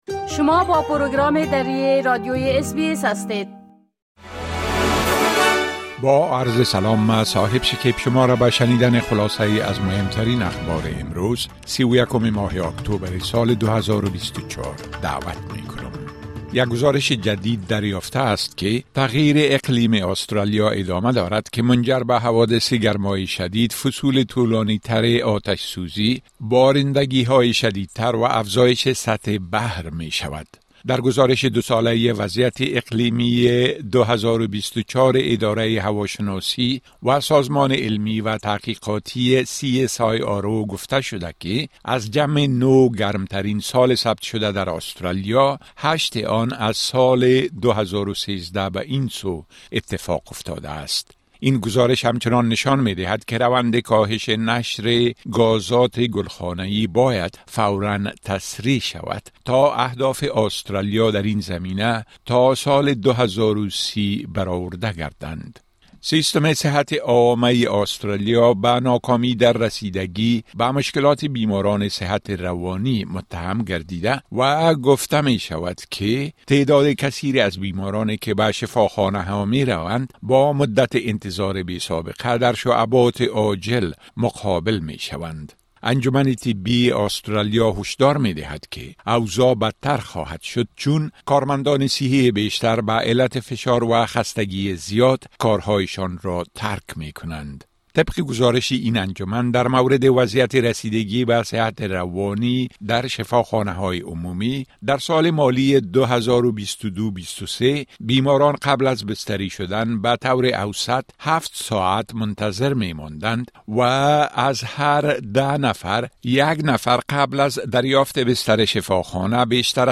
10 am News Update Source: SBS / SBS Filipino